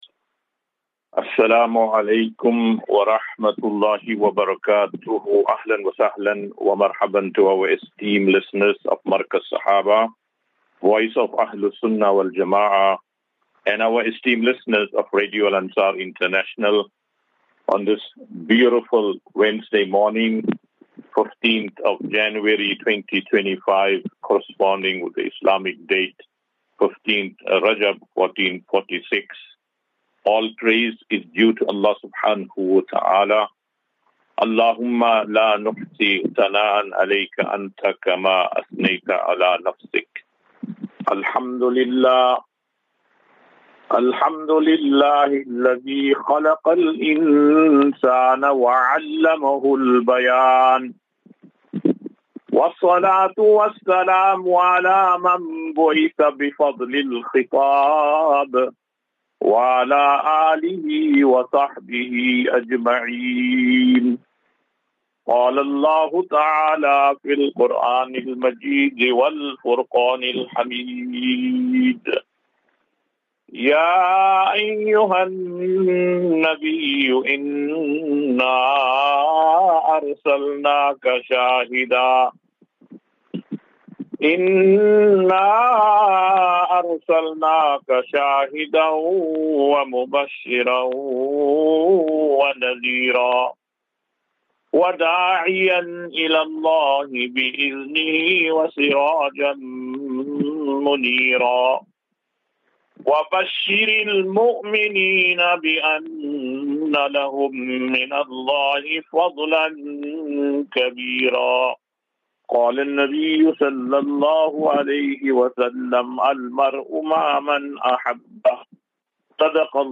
As Safinatu Ilal Jannah Naseeha and Q and A 15 Jan 15 January 2025.